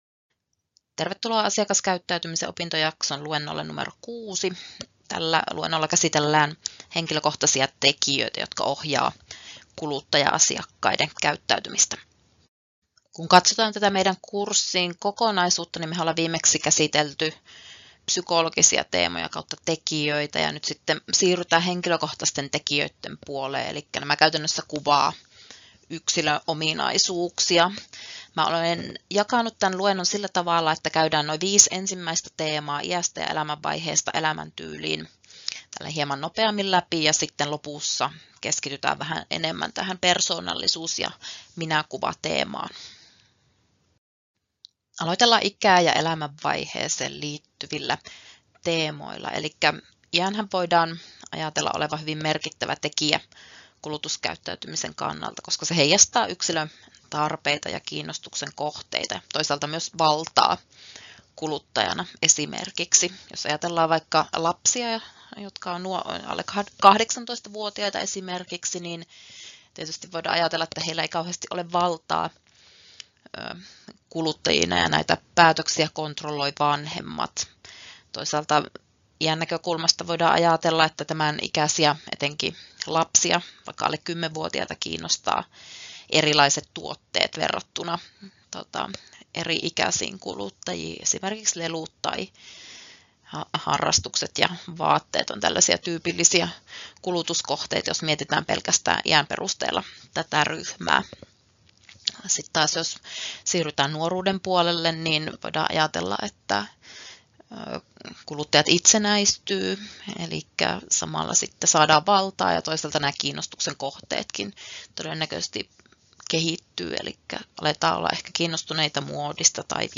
Luento 6: Henkilökohtaiset tekijät — Moniviestin